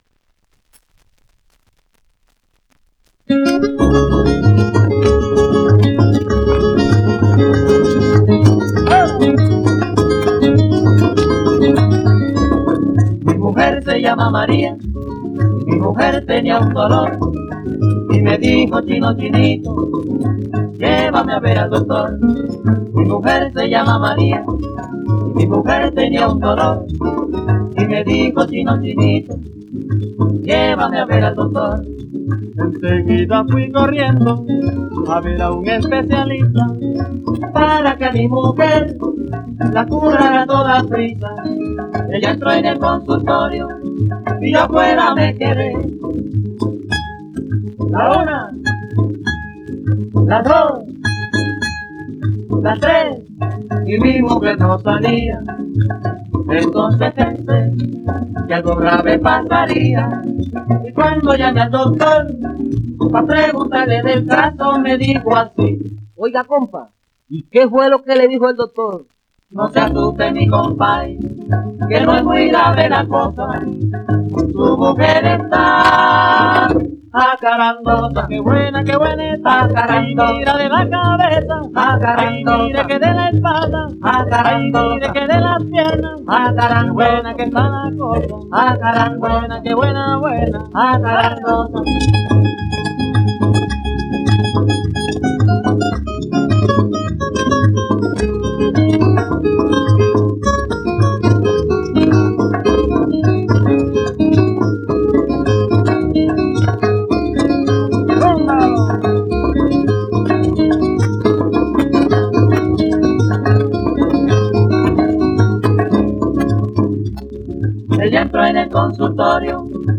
1 disco : 78 rpm ; 25 cm Intérpretes